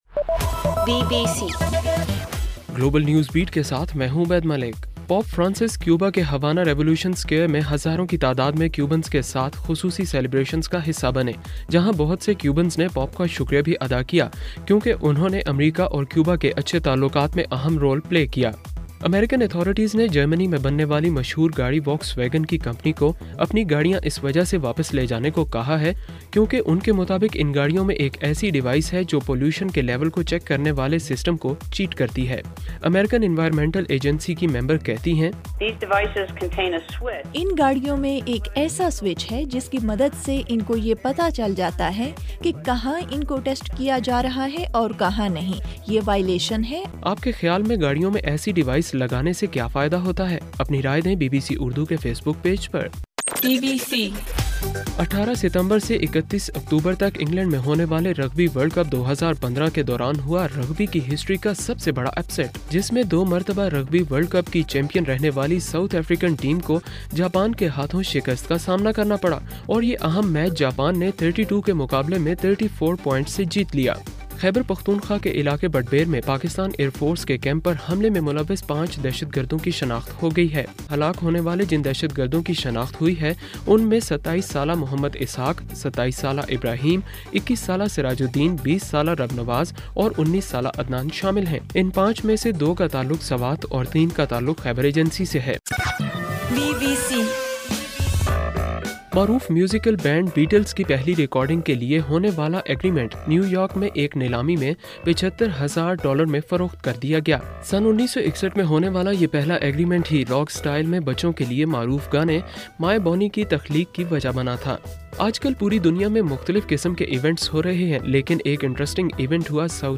ستمبر 20: رات 8 بجے کا گلوبل نیوز بیٹ بُلیٹن